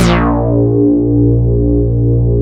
P.5 A#2 1.wav